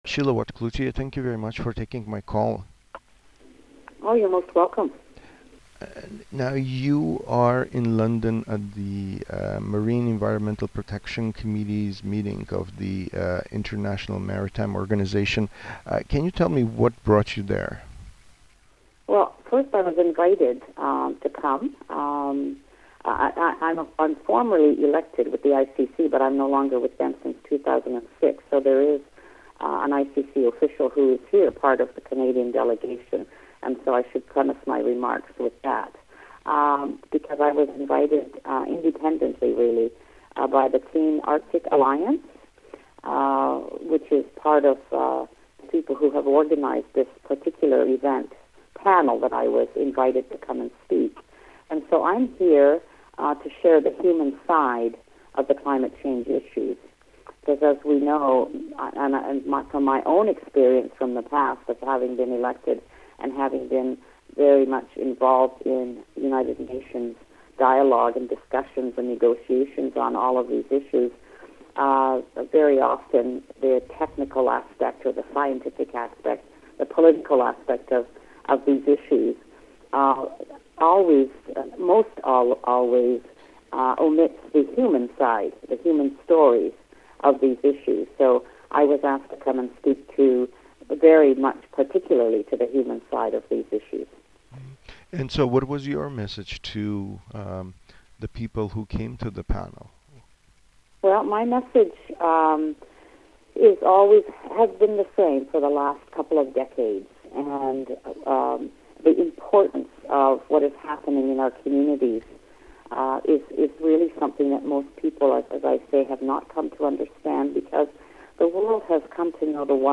“It’s one of the biggest threats because heavy fuel oil is extremely toxic and of course the possible potential for spillage into our Arctic marine ecosystem is deadly to us,” Watt-Cloutier said in a phone interview from London, where she participated in a panel discussion on the issue on the sidelines of the MEPC meeting.
Full interview with Sheila Watt-Cloutier